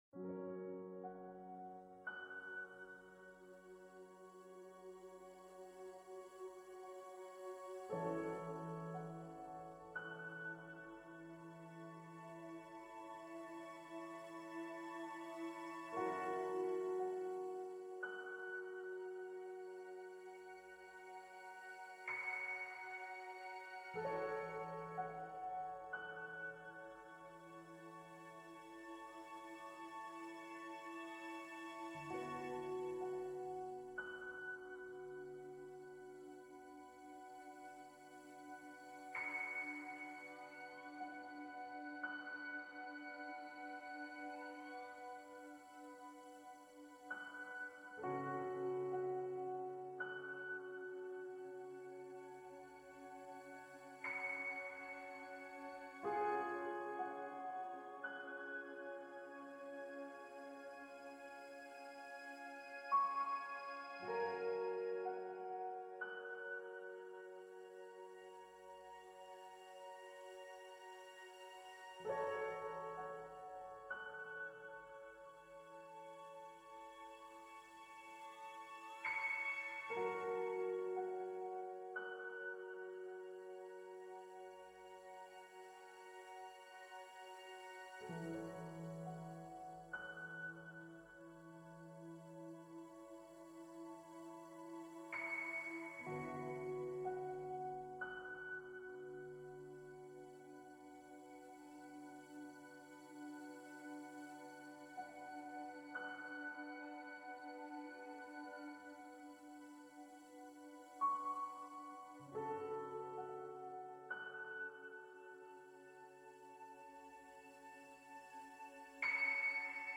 piano_piano.ogg